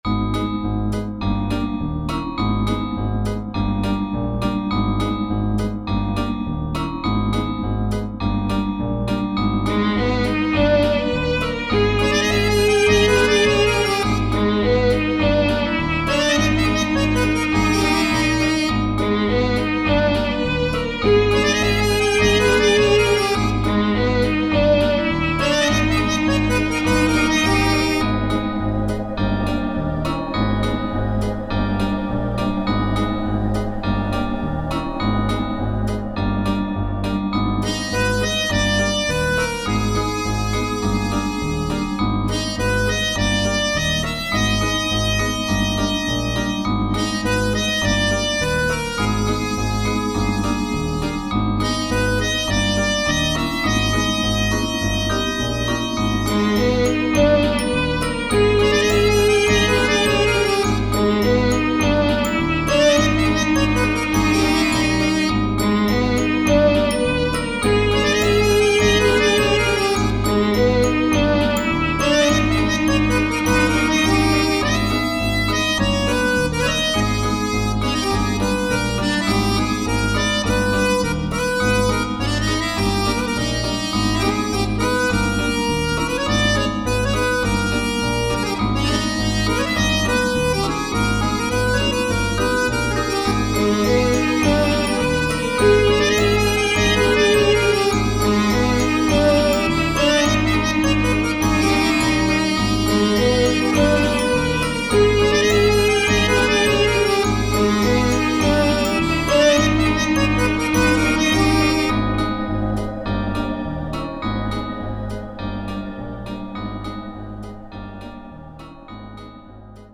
ハロウィン×料理をイメージしたBGM。 ハロウィン系のサウンドにヴァイオリンとアコーディオンの掛け合いが特徴的。